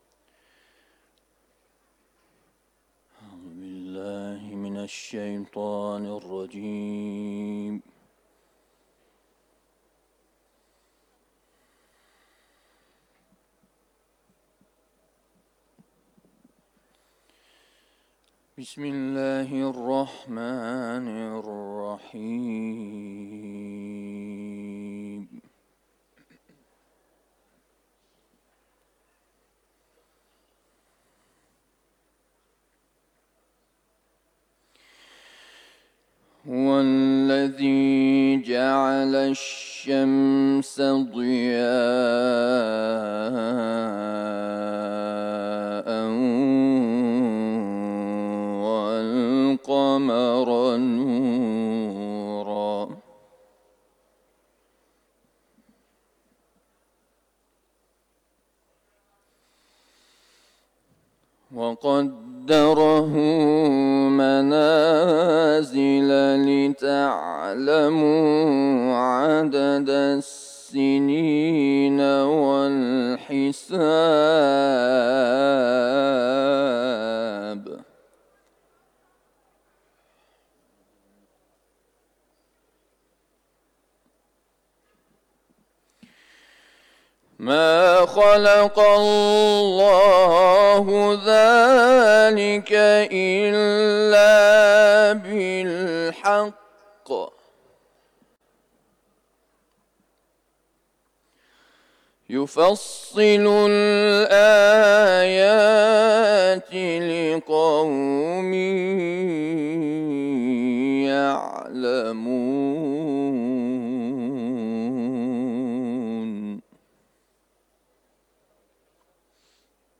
تلاوت
حرم مطهر رضوی ، سوره یونس